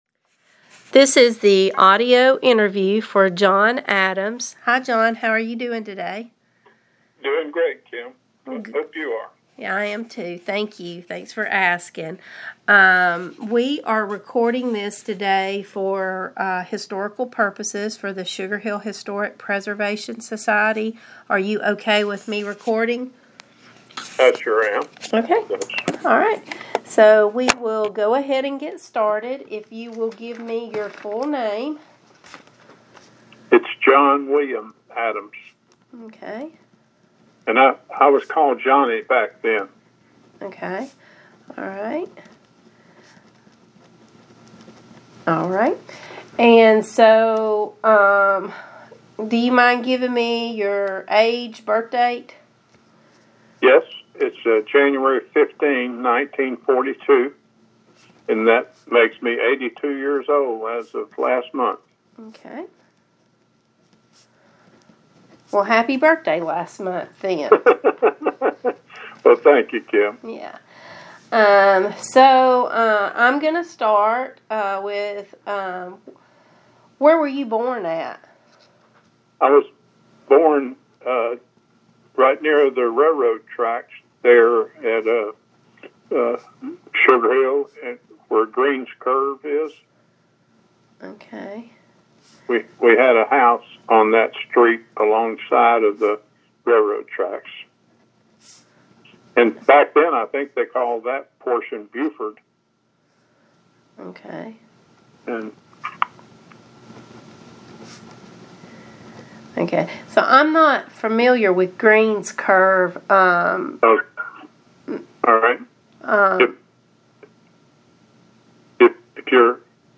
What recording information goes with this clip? via telephone